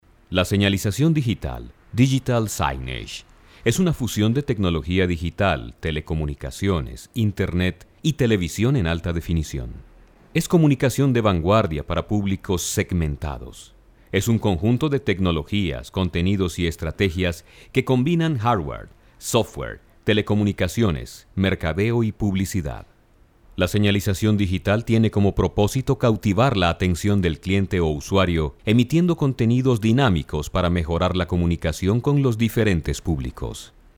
Voz muy vendedora, con bajos naturales, atractiva, versátil. Excelente vocalización e interpretación. Español Neutro.
Sprechprobe: Industrie (Muttersprache):
Have a wonderful rhythm, and several tones.